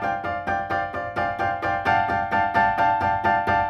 Index of /musicradar/gangster-sting-samples/130bpm Loops
GS_Piano_130-D1.wav